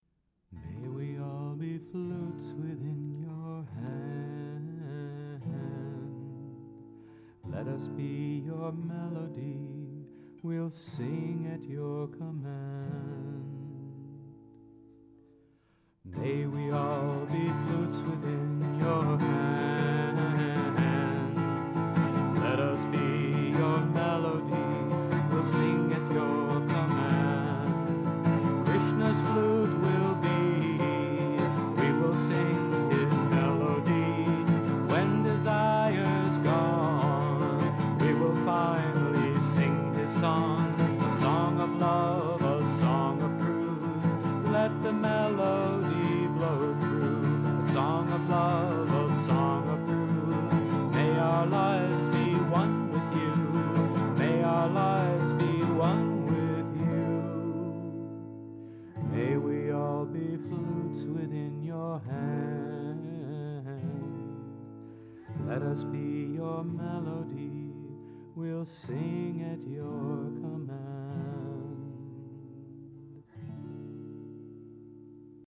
1. Devotional Songs
Harmonic Minor 8 Beat  Men - 4 Pancham  Women - 1 Pancham
Harmonic Minor
8 Beat / Keherwa / Adi